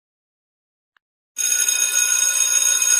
Bell Ring
Bell Ring is a free sfx sound effect available for download in MP3 format.
069_bell_ring.mp3